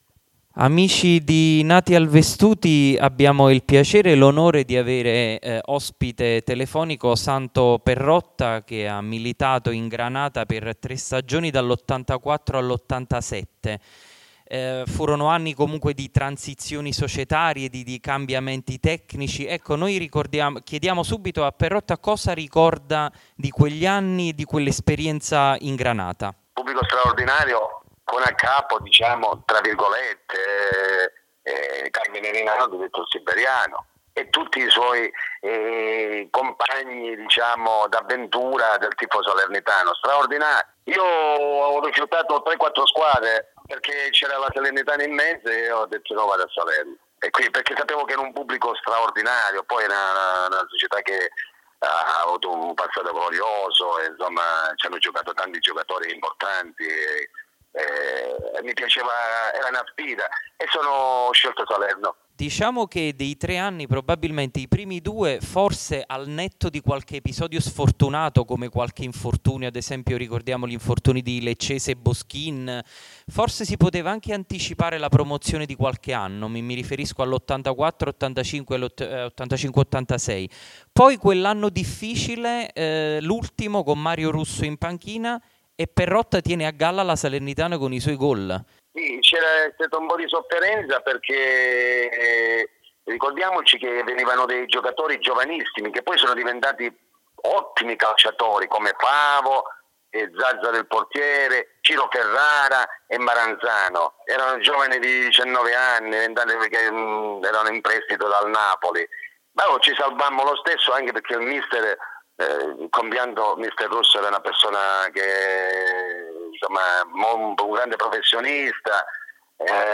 Interviste Lascia un commento